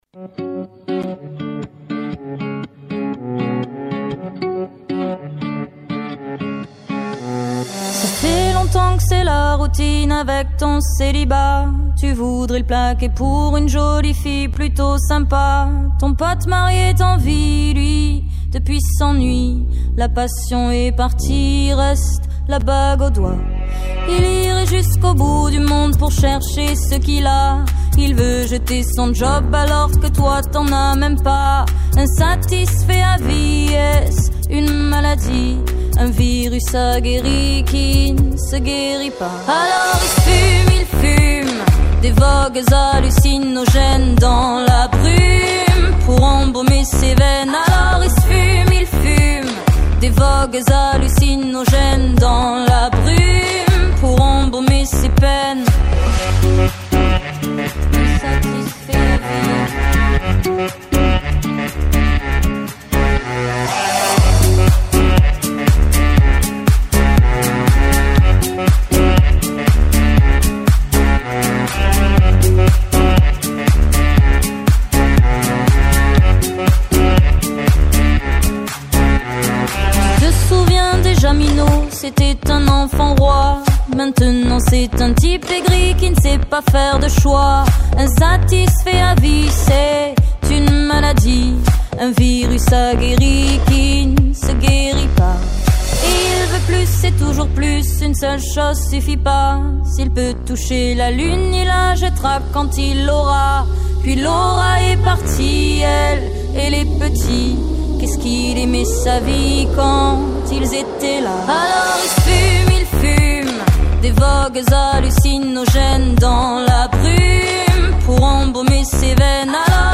Entretien avec Suzane, le phénomène électro du moment